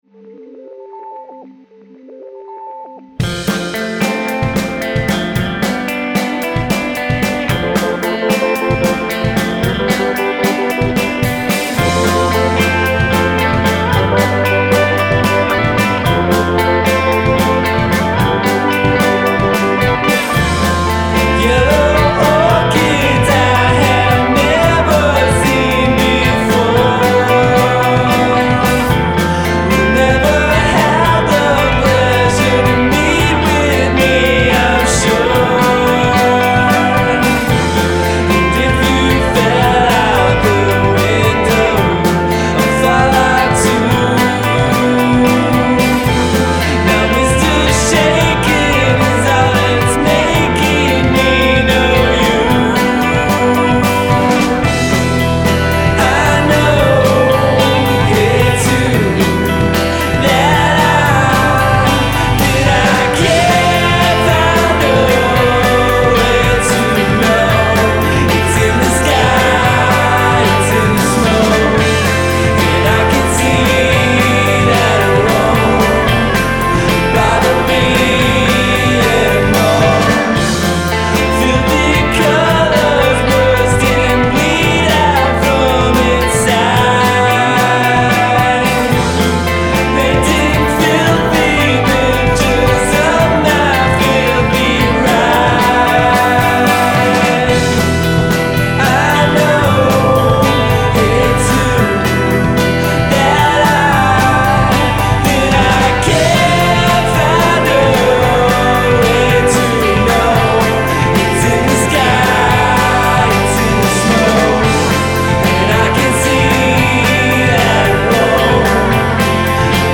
Genre: Psychedelic / Stoner Groove